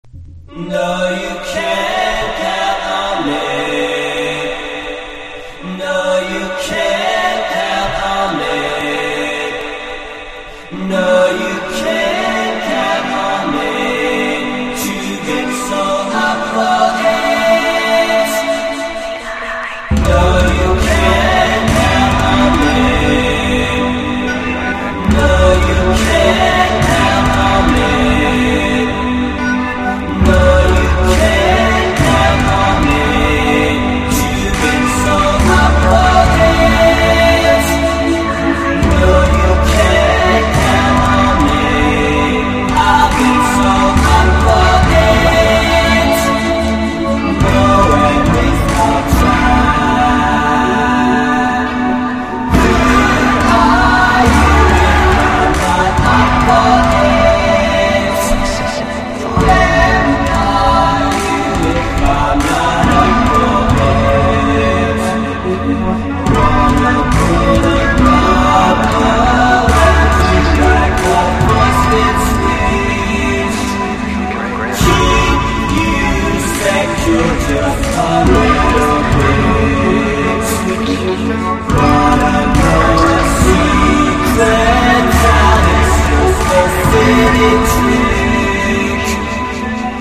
1. 10s ROCK >
SHOEGAZER / CHILLWAVE / DREAM POP